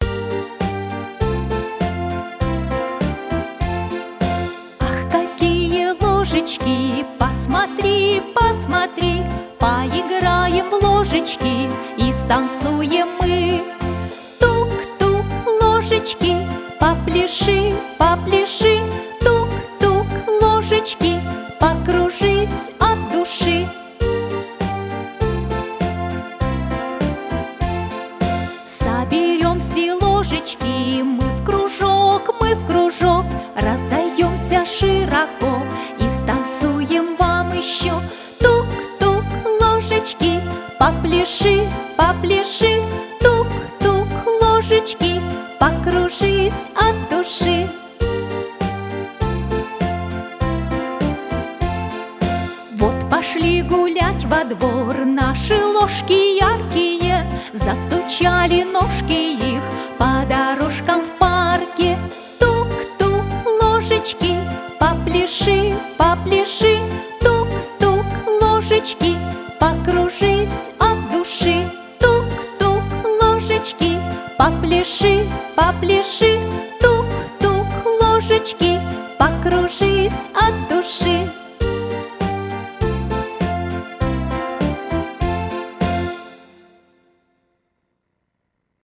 Поёт взрослый.
Слушать плюс: